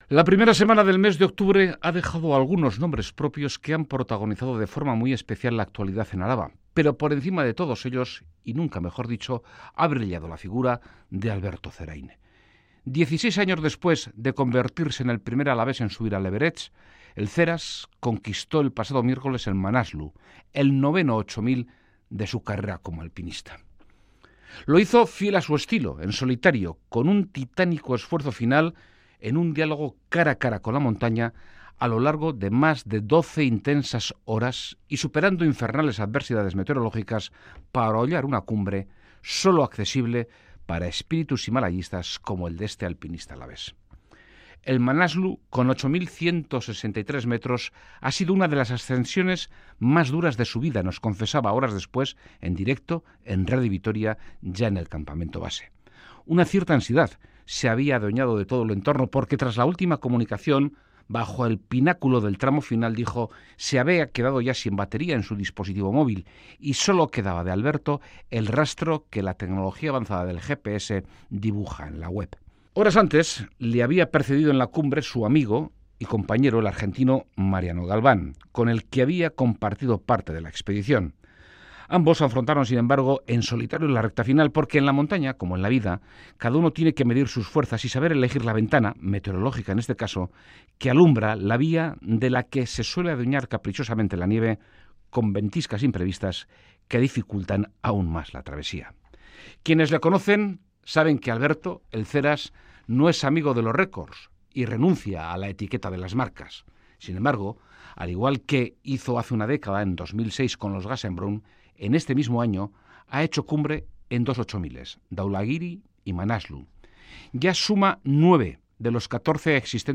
Audio: El Manaslu con 8.163 metros ha sido una de las ascensiones más duras de su vida, nos confesaba horas después en directo en Radio Vitoria.